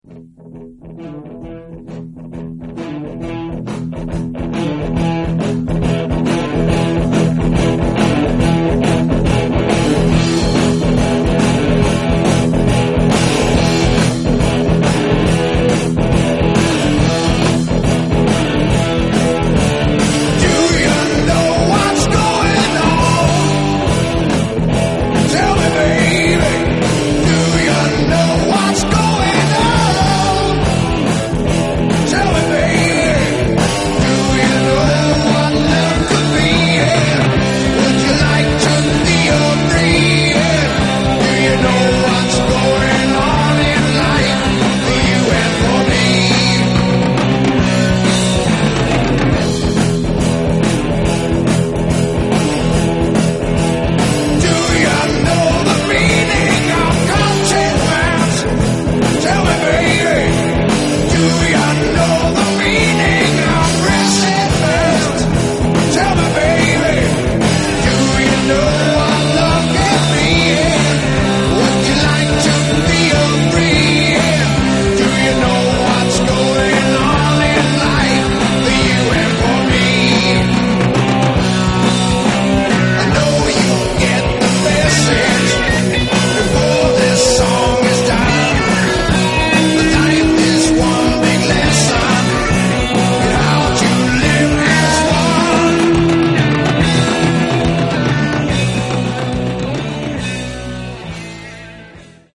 hard rock band